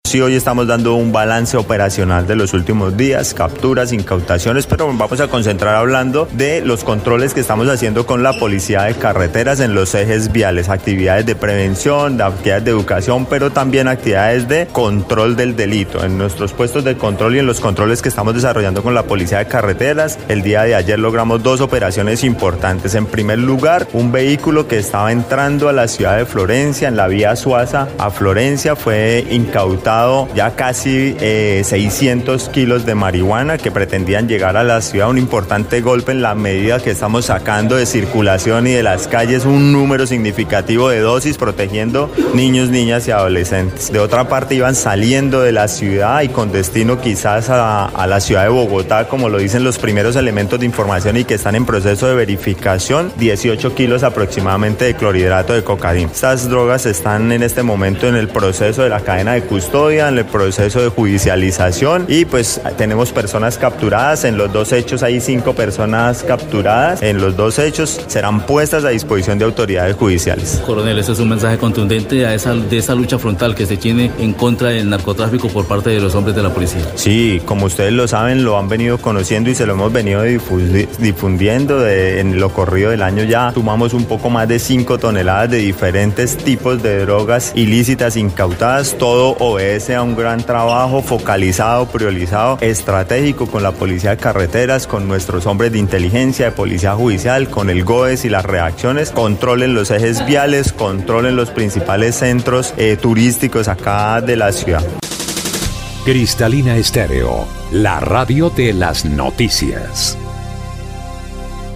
El coronel César Yovanny Pinzón Higüera, comandante de la Policía Caquetá, explicó que, el alijo con la marihuana pretendía ingresar al departamento con destino al sur del continente, mientras que la pasta base de coca, llevaba rumbo a la capital del país.
01_CORONEL_CESAR_PINZON_OPERATIVOS_1.mp3